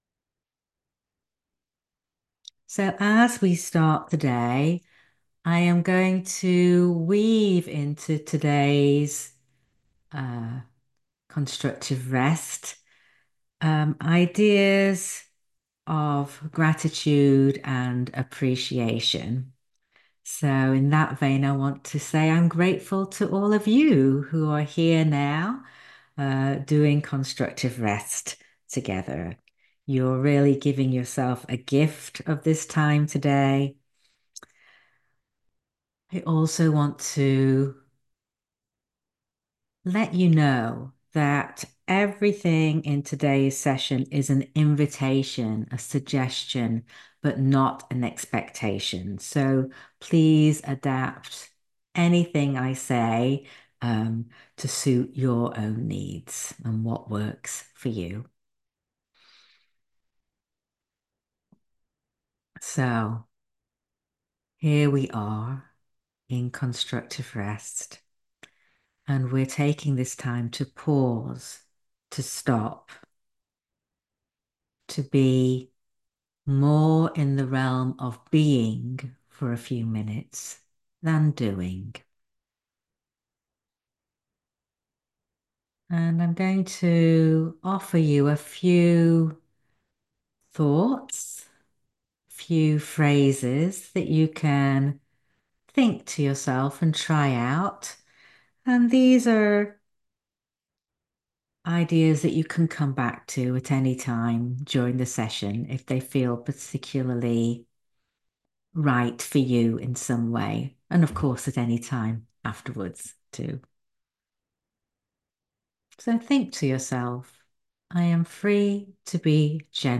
talk given during a live session on November 21, 2024. Listen to it during Constructive Rest to guide your thinking and awareness.